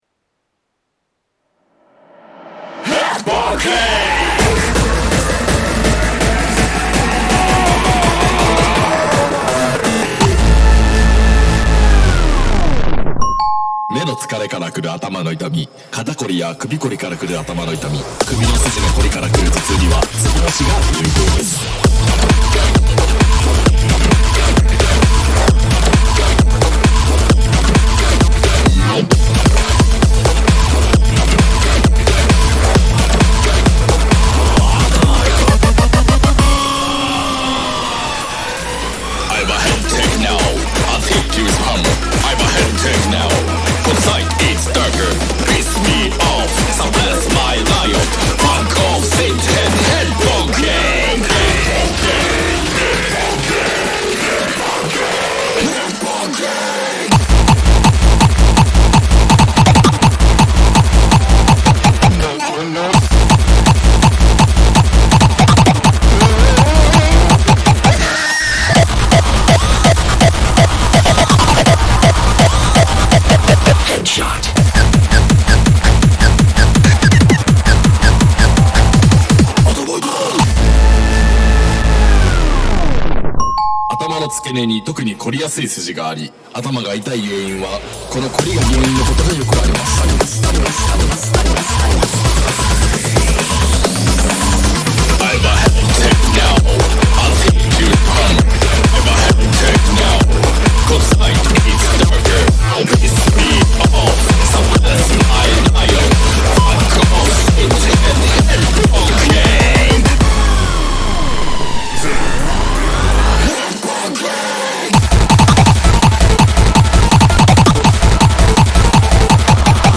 BPM132-165
Comments[BASS TO XTRARAW]